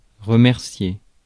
Ääntäminen
remercier France (Paris)